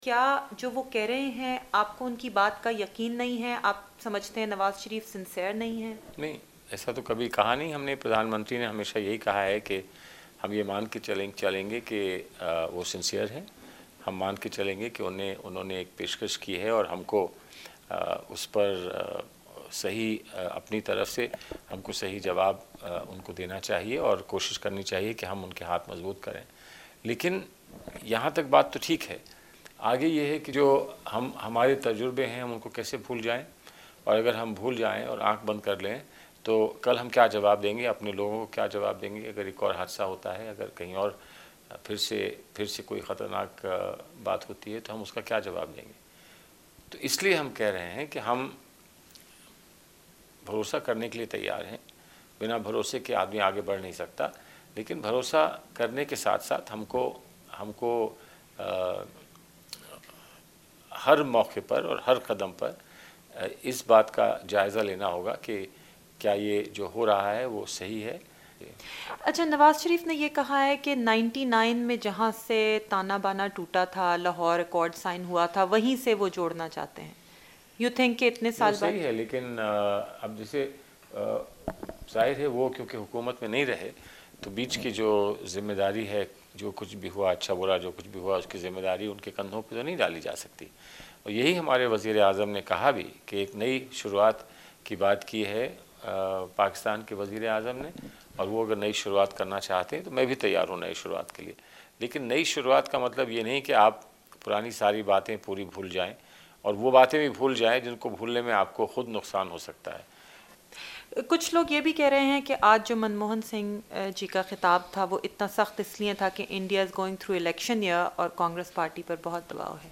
بھارتی وزیر خارجہ سلمان خورشید کی وی او اے سے خصوصی گفتگو